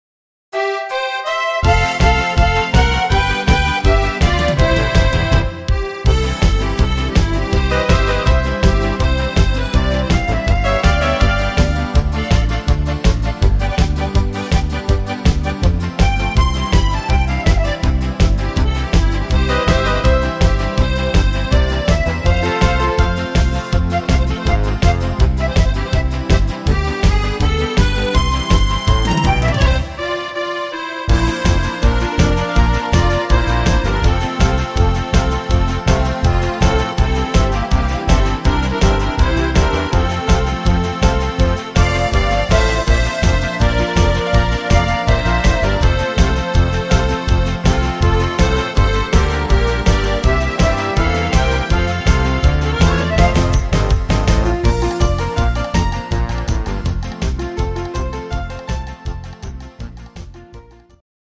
Rhythmus  Polka
Art  Deutsch, Volkstümlich